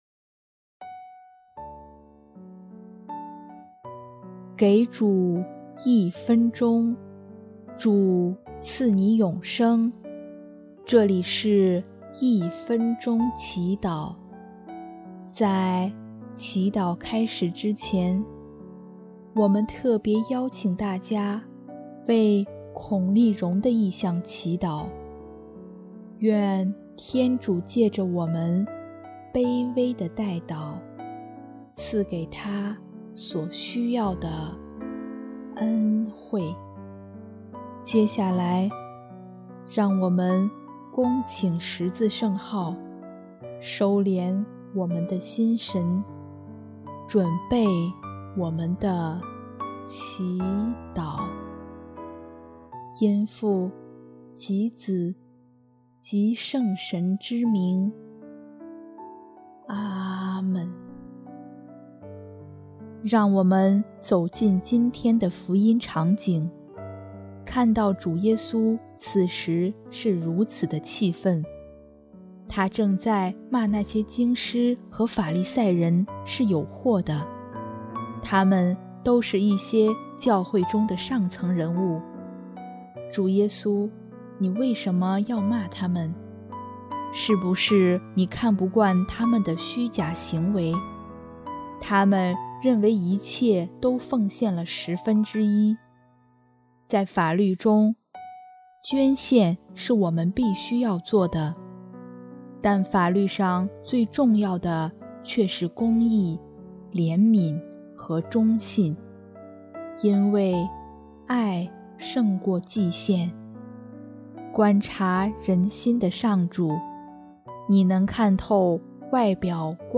【一分钟祈祷】|8月27日 仁爱胜过祭献